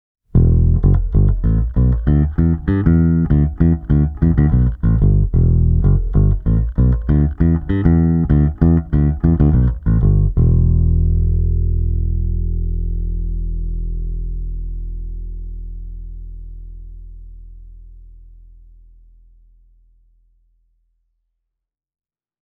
The Violinbirch comes equipped with a Music Man-style large humbucker.
Played acoustically the Violinbirch’s sound is all about clarity seasoned with a nice dose of mid-range gnarl.
Through an amp the Violinbirch comes across with a strong voice with plenty of attitude in the middle register.
harjunpacc88acc88-violinbirch-through-ashdown-combo.mp3